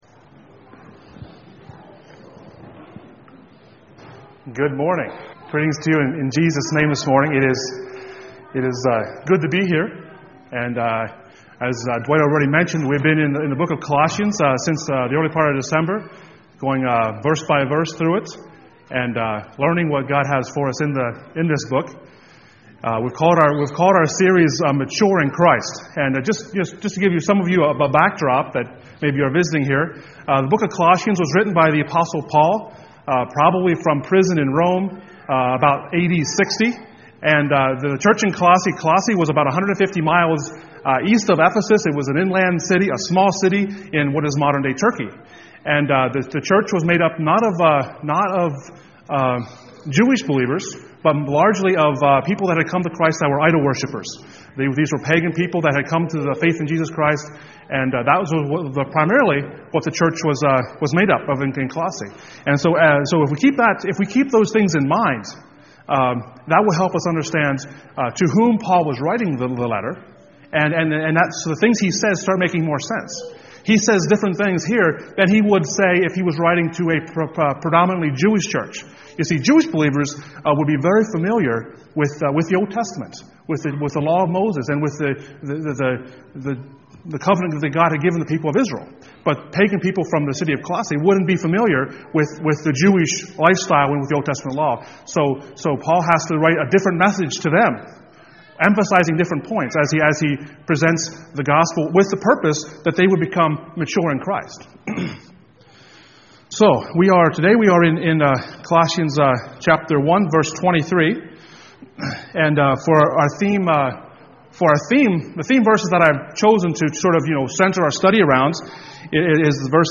Passage: Colossians 1:19-23 Service Type: Sunday Morning